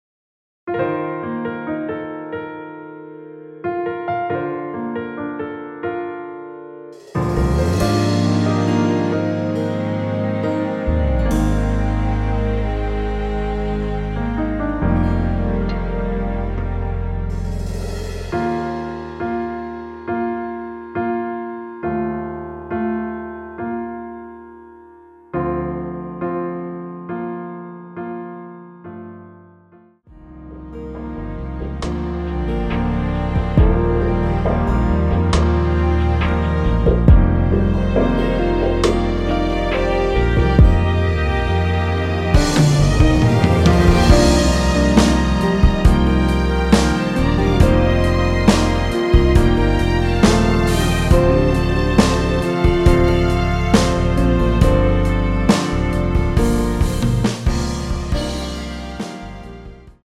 원키에서(-5)내린 MR입니다.
Bb
앞부분30초, 뒷부분30초씩 편집해서 올려 드리고 있습니다.
중간에 음이 끈어지고 다시 나오는 이유는